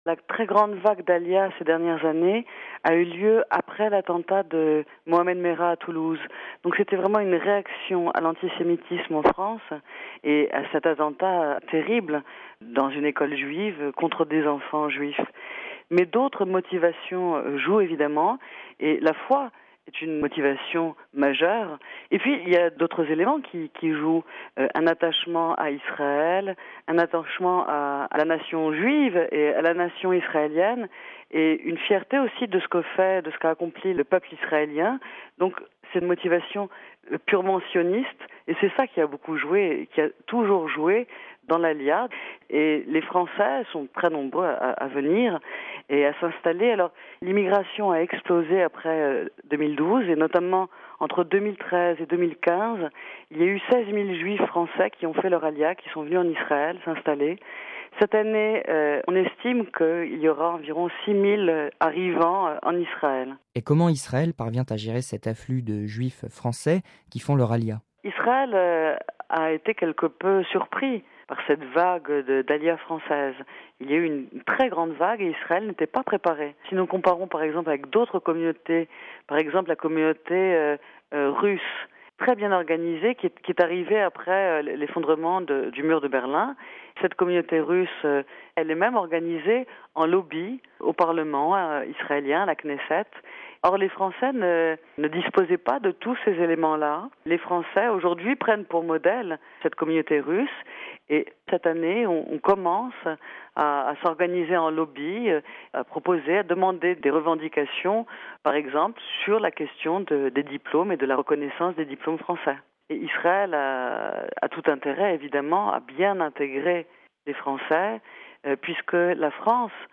(RV) Entretien - Le 19 mars 2012, Mohammed Merah abat un rabbin et ses deux enfants, ainsi qu’une autre fillette dans l’école juive Otzar Hatorah à Toulouse, dans le sud-ouest de la France.